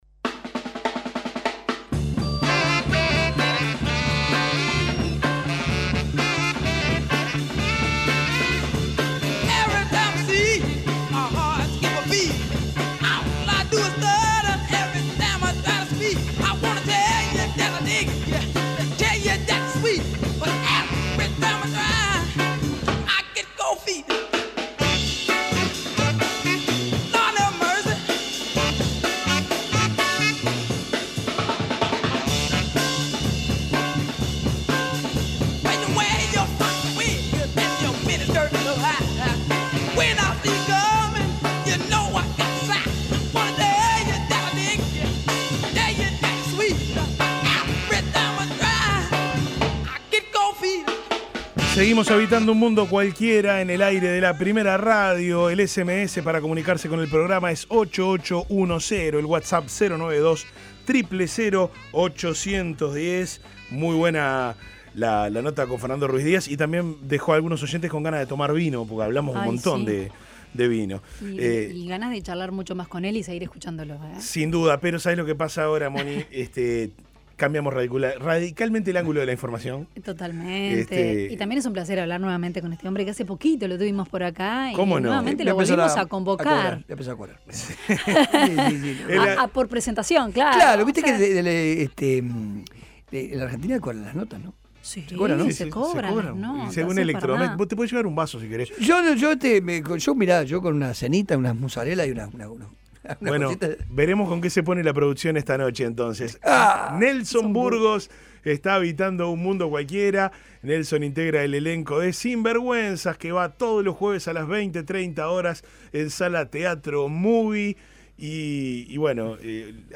Dos de sus protagonistas contar más sobre esta obra pasaron en Un Mundo Cualquiera.